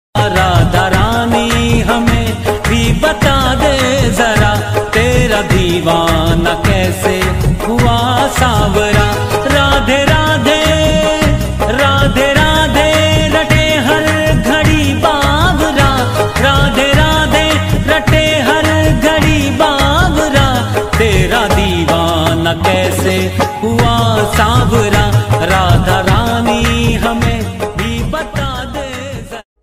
Bhakti Ringtones